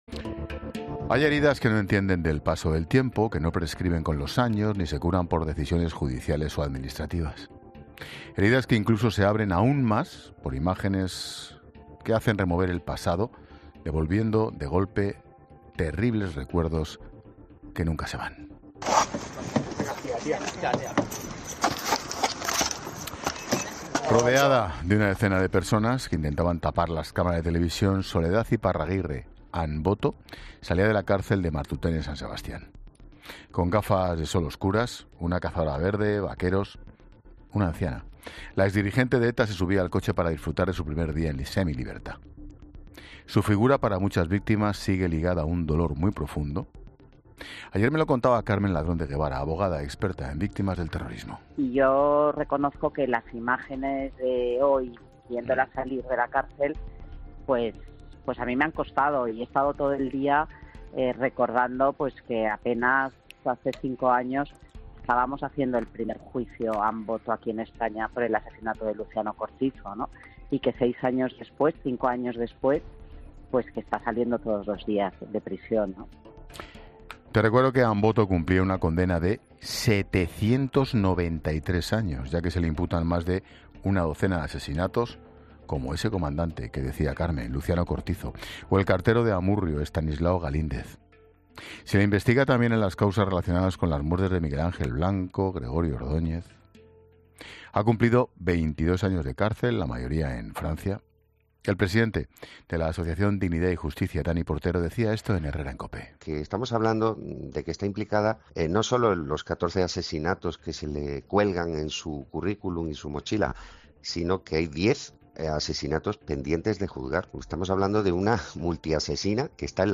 Un juez de vigilancia penitenciaria analiza en COPE las claves del artículo 100.2 que ha permitido la semilibertad de la exjefa de ETA Soledad...